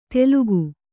♪ telugu